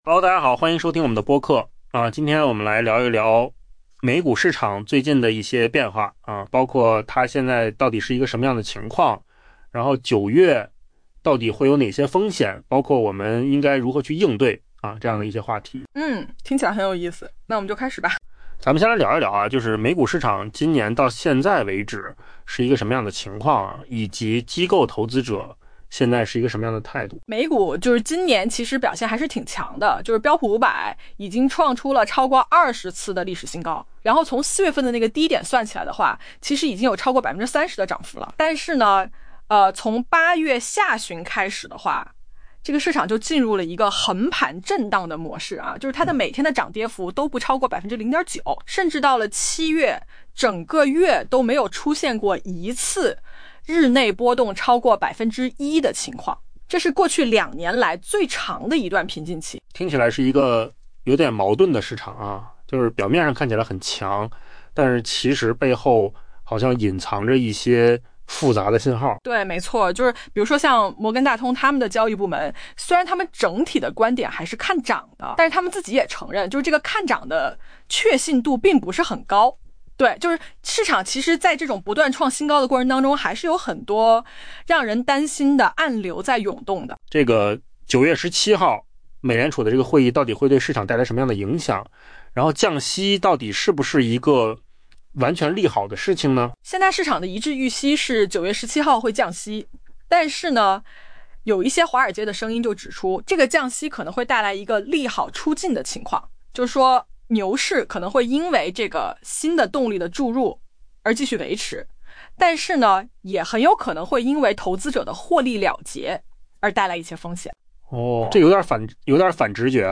AI 播客：换个方式听新闻 下载 mp3 音频由扣子空间生成 摩根大通交易部门警告，尽管美股涨势如虹，今年已创下 逾 20 次历史新高 ，但美联储的下一步行动可能抑制投资者的热情。